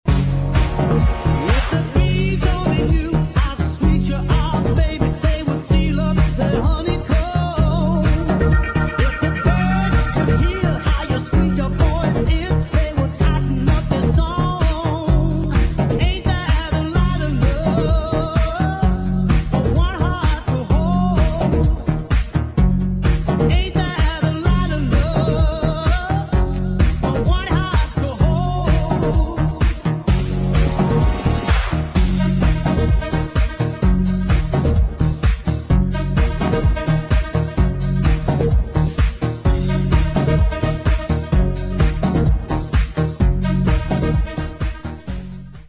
[ POP ROCK / HOUSE ]